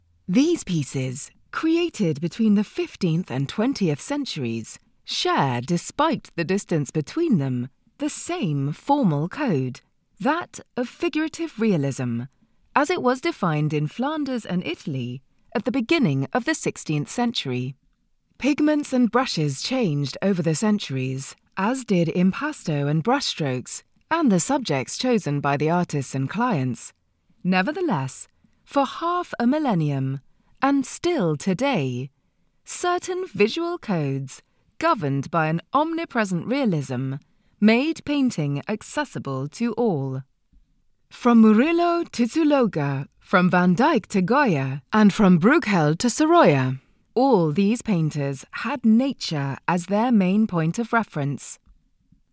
English (British)
Commercial, Natural, Versatile, Deep, Warm
Audio guide